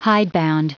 Prononciation du mot hidebound en anglais (fichier audio)
Prononciation du mot : hidebound